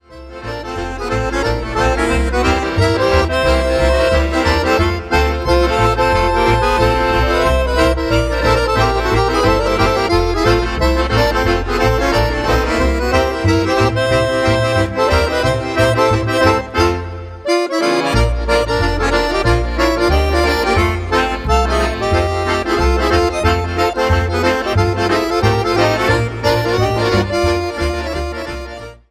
Fox